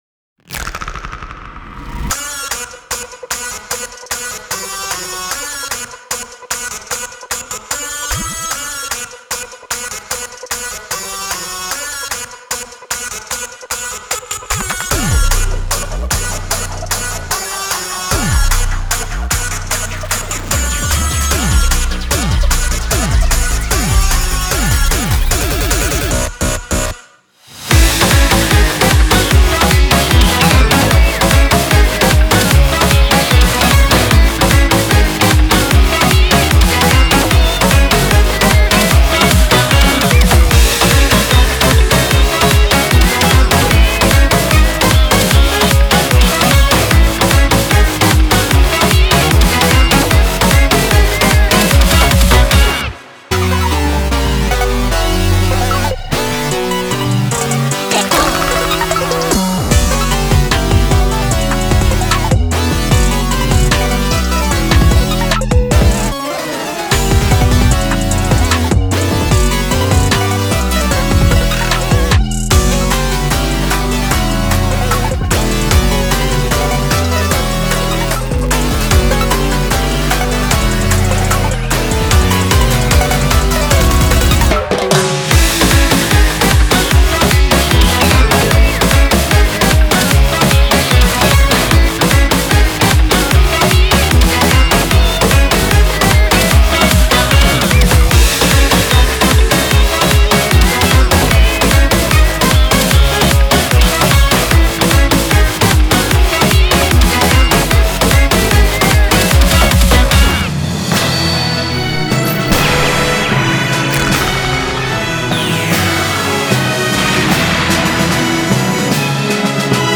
-instrumental-